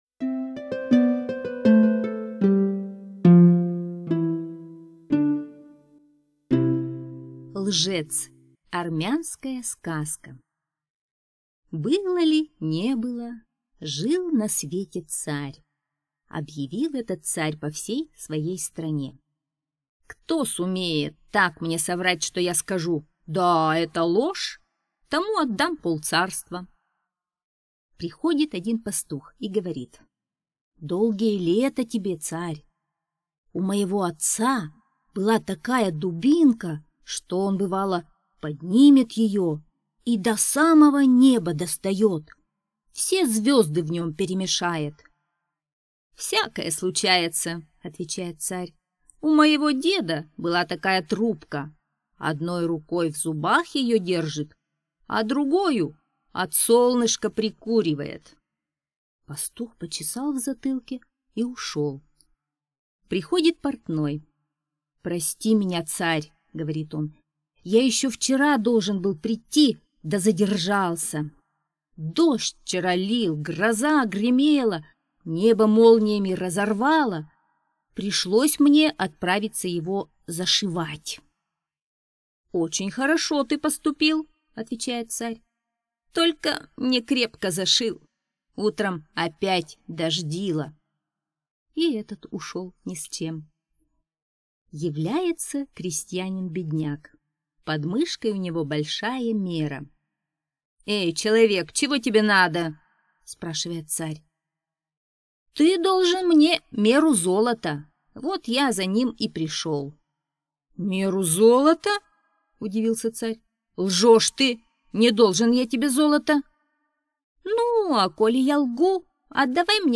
Лжец - армянская аудиосказка - слушать онлайн